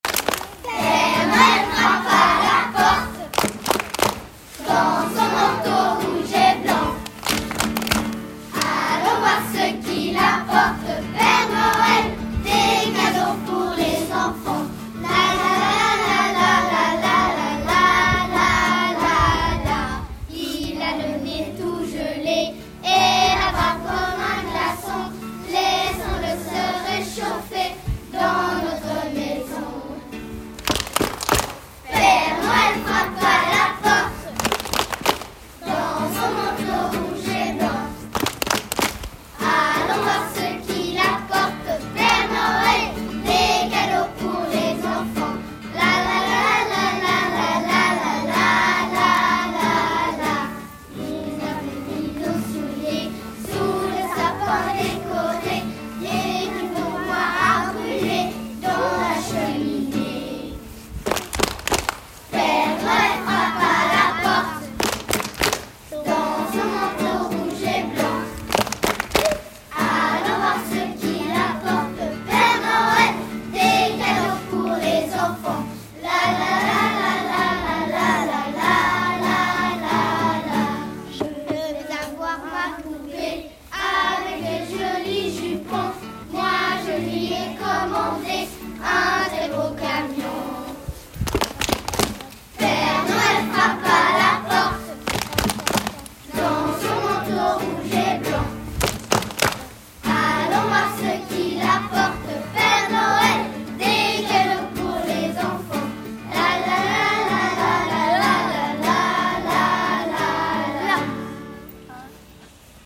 2021-22 : “Chantée de Noël”, les classes de Corcelles
Groupe 3 : classes 1-2P42, 5P41 et 3P42